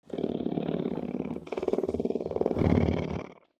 Big Wild Cat Long Purr Sound Button - Free Download & Play